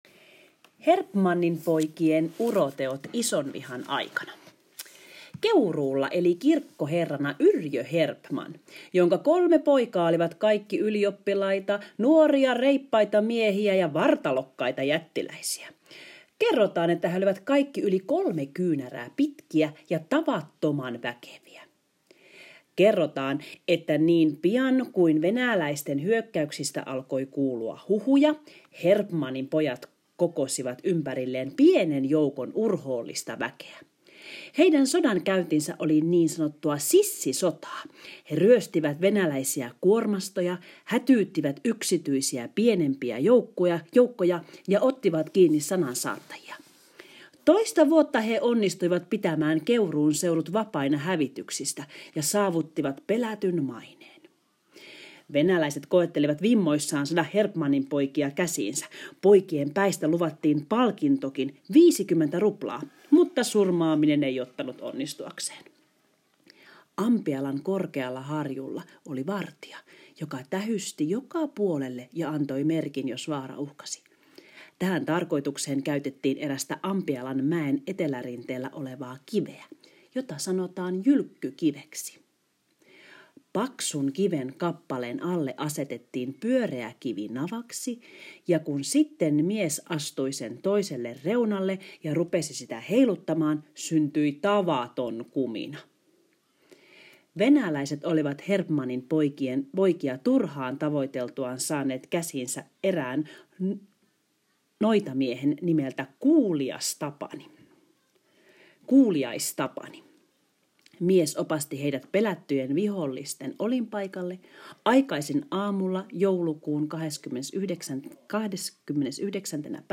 • Kuuntele opettajan lukema tarina ja vastaa kysymyksiin 6 ja 7 vihkoosi.